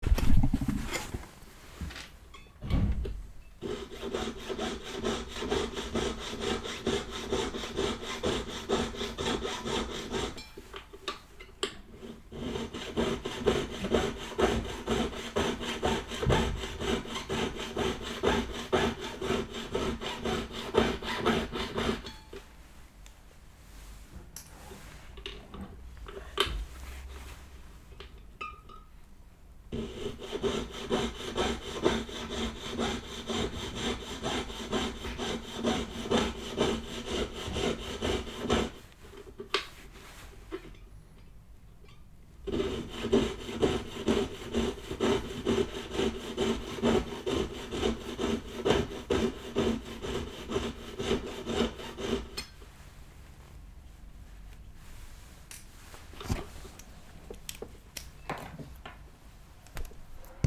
Goldsmith saw